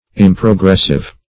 Meaning of improgressive. improgressive synonyms, pronunciation, spelling and more from Free Dictionary.